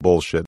Download Barack Obama Say Bullshit sound effect for free.
Barack Obama Say Bullshit